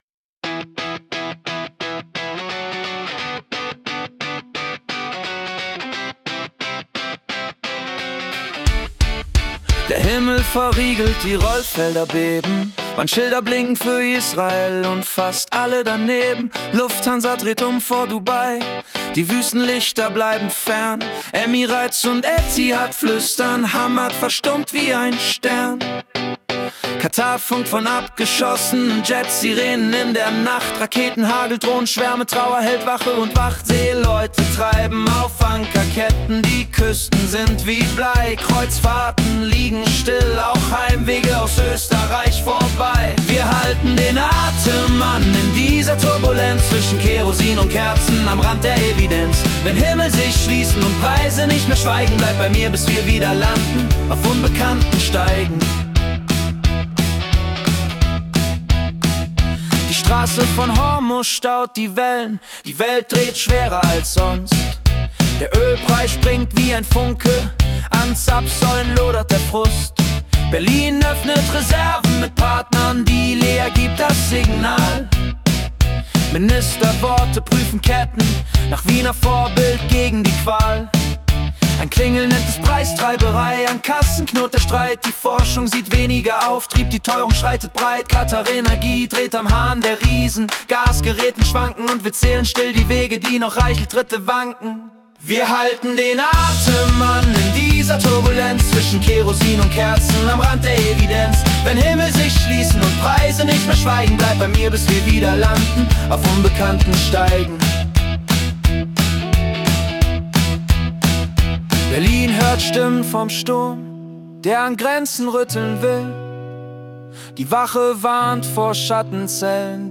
Die Nachrichten vom 13. März 2026 als Singer-Songwriter-Song interpretiert.